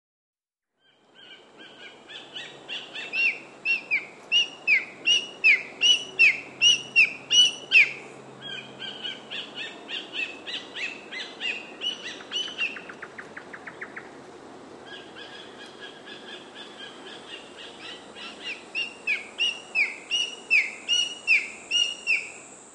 What do I do? I call like a whistle, “wee-choo, wee-choo”